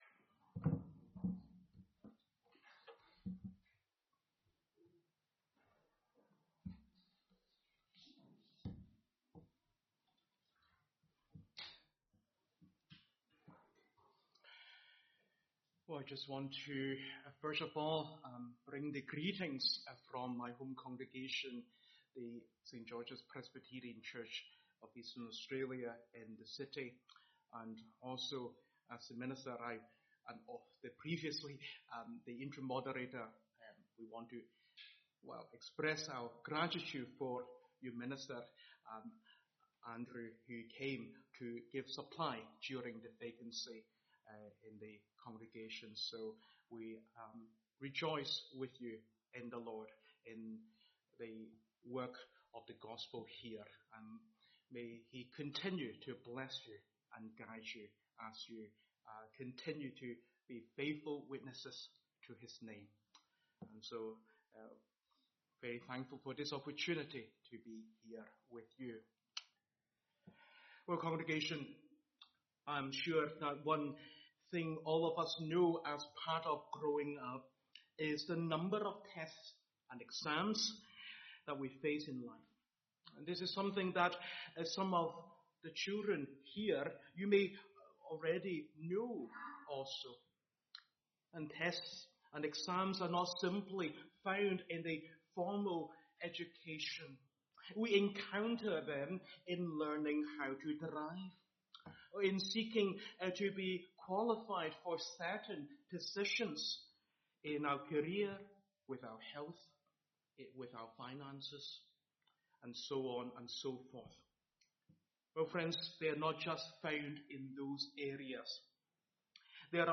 Sermons , Visiting Speakers